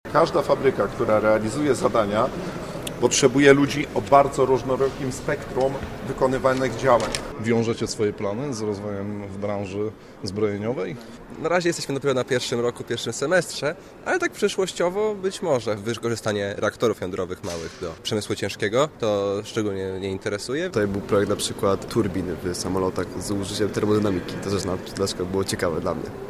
Równolegle do konferencji w holu budynku Wydziału Architektury i Inżynierii Zarządzania Politechniki Poznańskiej, odbyły się targi pracy branży zbrojeniowej. Rozmowom na targach przysłuchiwał się nasz reporter: